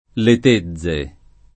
le t%zze] top. — continuaz. (settentr.) del pl. lat. attegiae [att$Je], rara voce (region.) per «capanne»: nome di parecchi centri abitati, tra i quali il comune di T. sul Brenta (Ven.) — pn. loc. quasi dappertutto con -z- sonora e con l’art. le conservato; ma con -e- aperta nei comuni di Carpenedolo (Lomb.) e Mazzano (Lomb.), chiusa in quelli di Trenzano (Lomb.) e Vazzola (Ven.), oscillante in altri